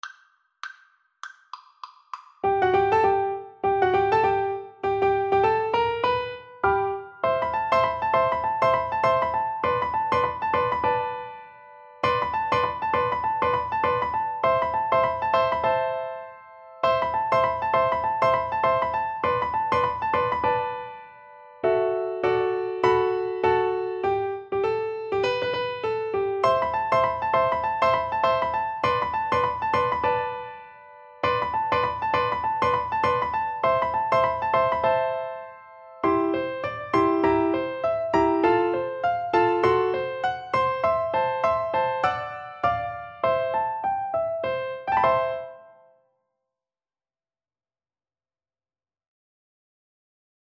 Fast swing =c.200
Jazz (View more Jazz Piano Duet Music)